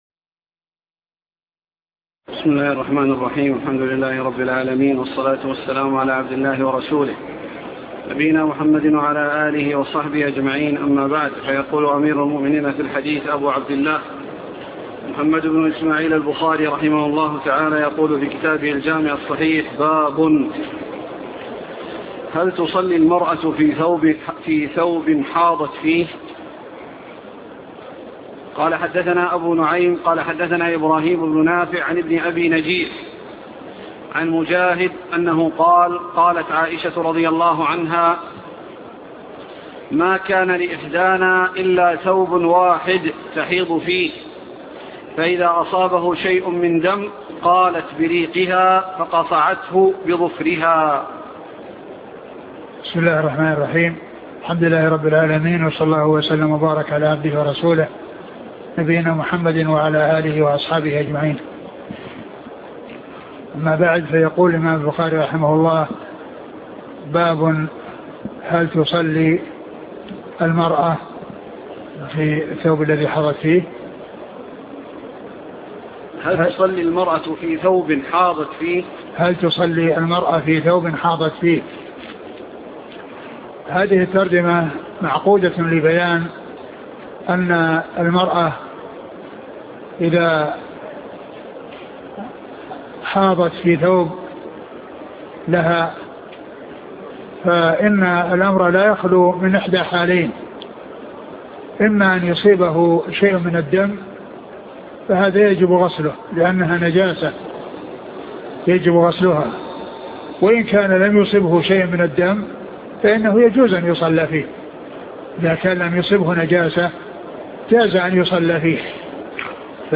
شرح صحيح البخاري الدرس الثامن والخمسون